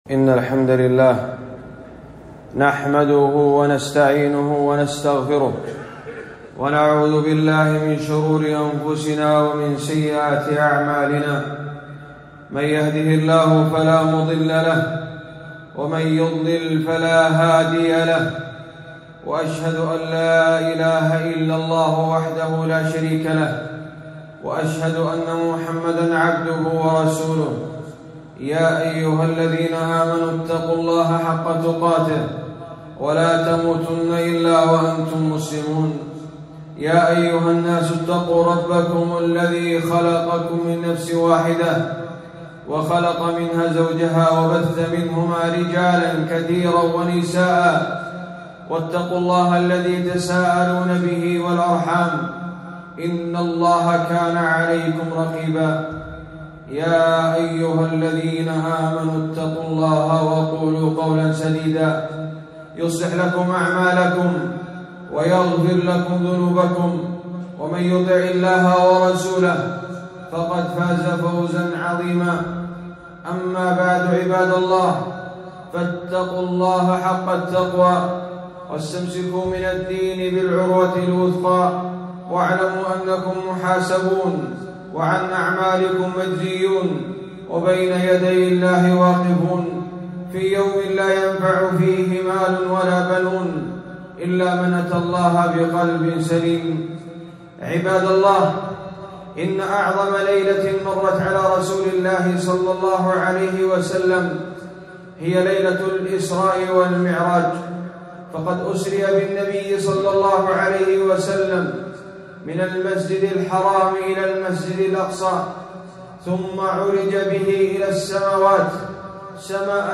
خطبة - النوم عن الصلوات